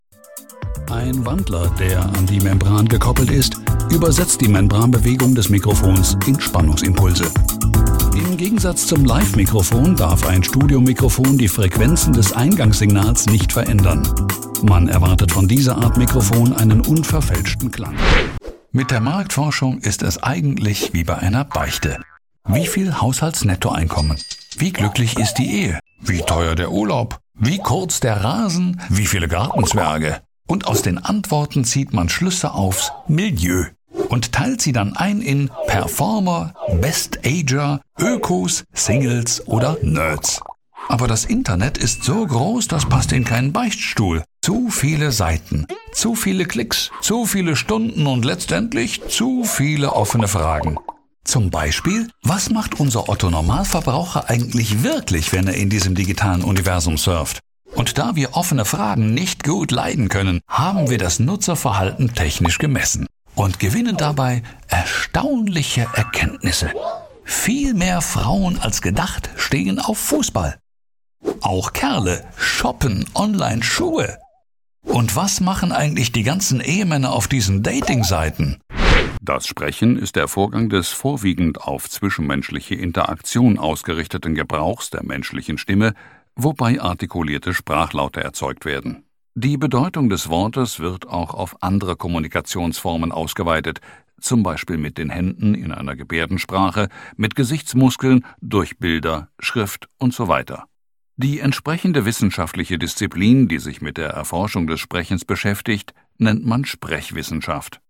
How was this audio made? I record from my fully equipped studio, delivering high-quality audio with a fast turnaround–depending on project scope and availability–to help you communicate your message effectively and connect authentically with your audience.